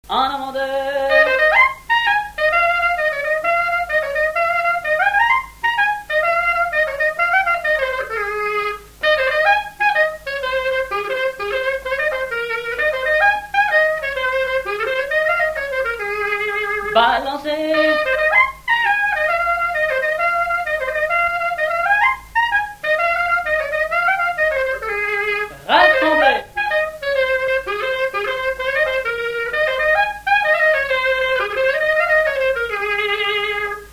Avant-deux
Résumé instrumental
danse : branle : avant-deux
Pièce musicale inédite